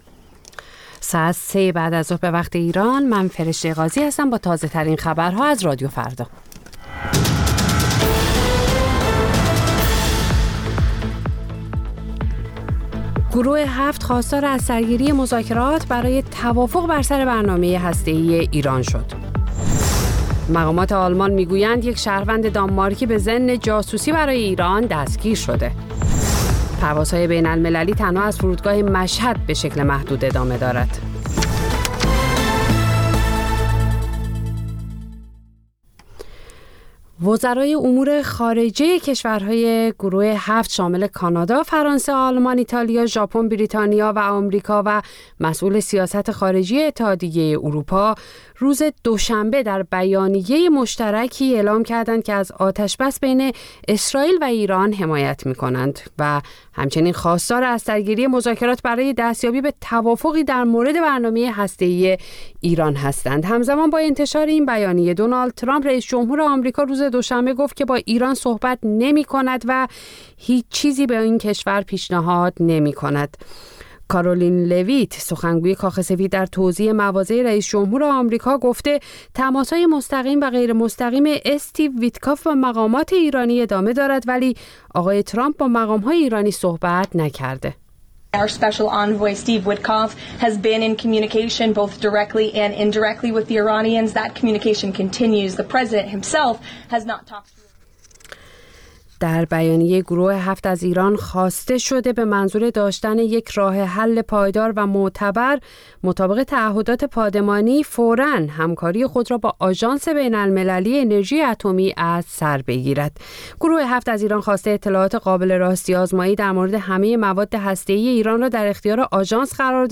پخش زنده - پخش رادیویی